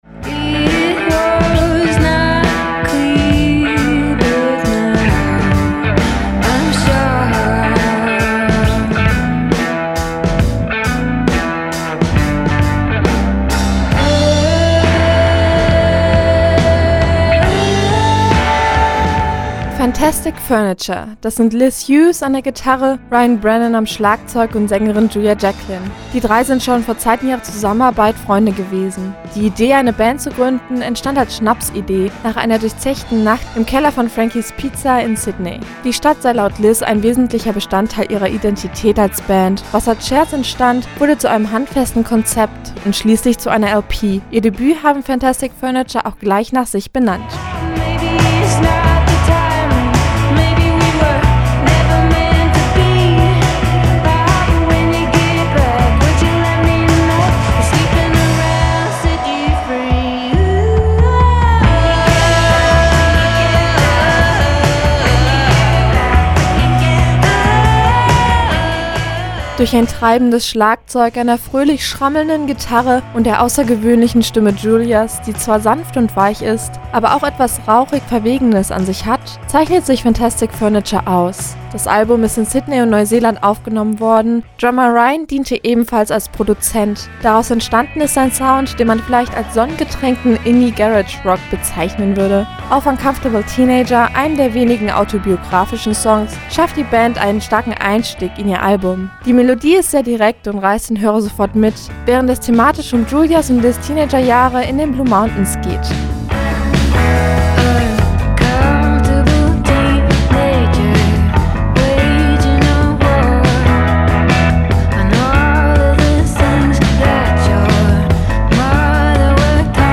Was letztendlich herauskam könnte man sonnengetränkten Garage Indie-Rock nennen. Ihre australischen Wurzeln hört man definitiv aus ihrem coolen, leicht grungigen Sound heraus.
Phantastic Ferniture bewegen sich zwischen tanzbaren Rythmen, authentischen Melodien und einer schlichten Coolness, die weder künstlich erzeugt oder vorgetäuscht werden kann und versüßen uns mit Ihrem Debüt die heißen Sommertage!